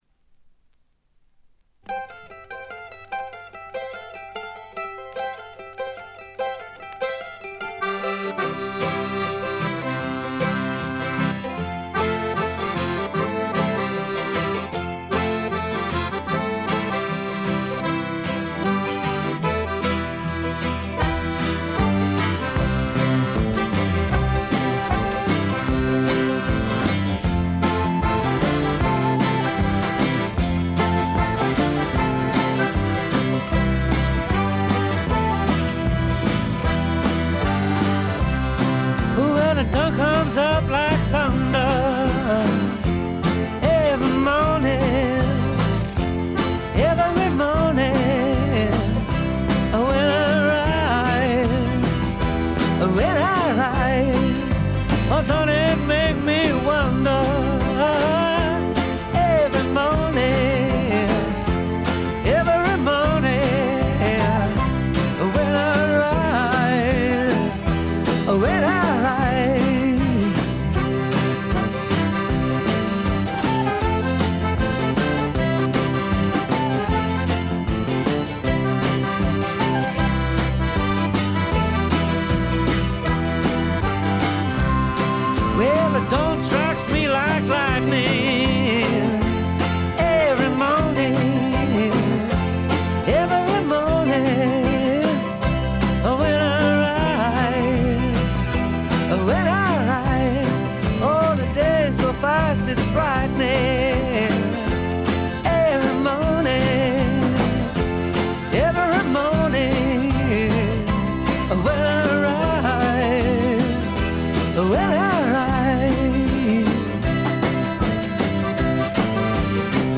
試聴曲は現品からの取り込み音源です。
guitar, mandolin, fiddle, keyboards, harmonica, vocals
violin, keyboards, harp, whistle
drums